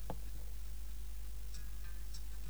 Here is Some Music From Nigerias Most Famous Muscian